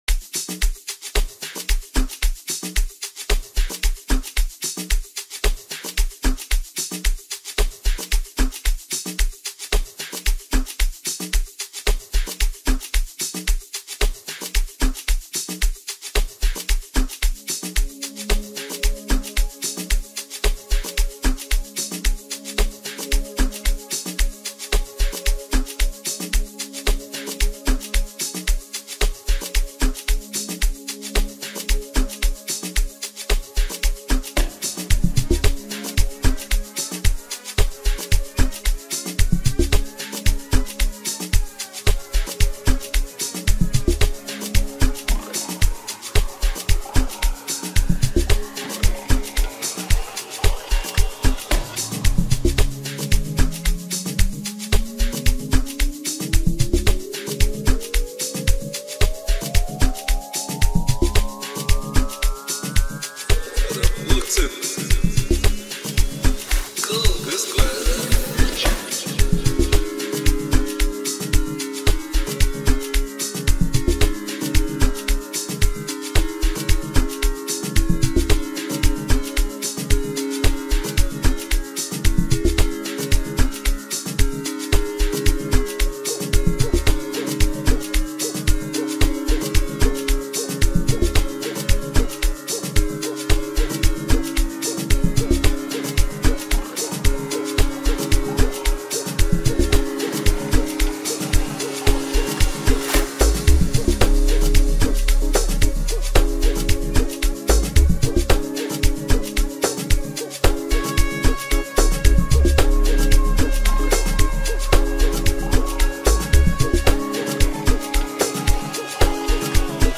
Home » Amapiano » DJ Mix » Maskandi